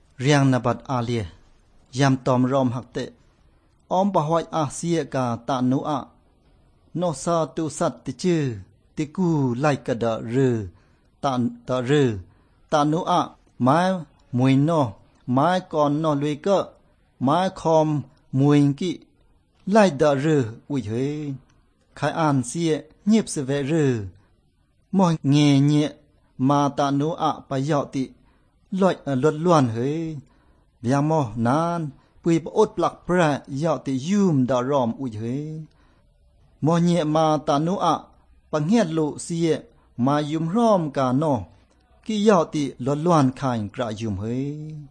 25 May 2014 at 2:01 pm tones and monosyllables
26 May 2014 at 11:37 am My first impression was something Polynesian with few consonants and glottal stops. After ten seconds or so it started sounding more tonal and with Chinese-like phonology.